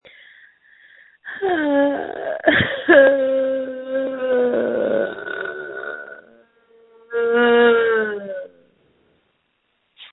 Screams from December 24, 2020
• When you call, we record you making sounds. Hopefully screaming.